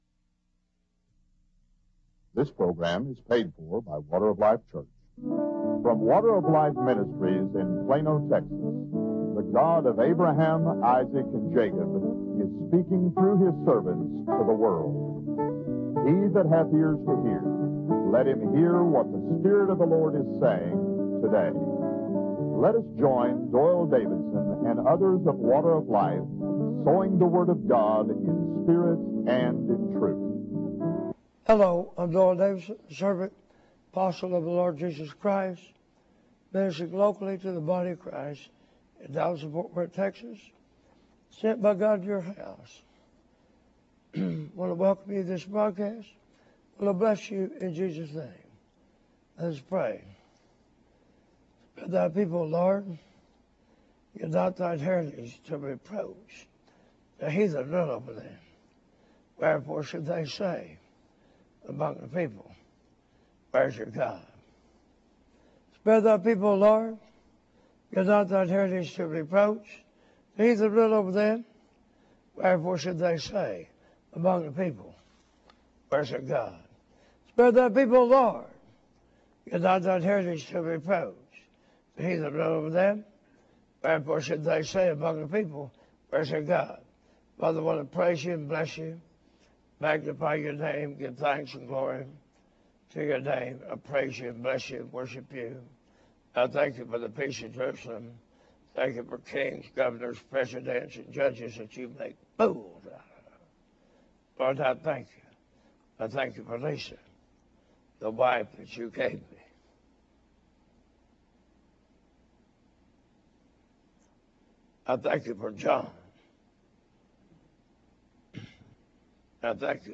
Video Recorded August 6, 2008 Posted: August 22, 2008 The broadcast below was recorded and aired live on the internet on August 6, 2008.